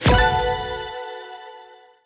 Appear_Wild_Sound.mp3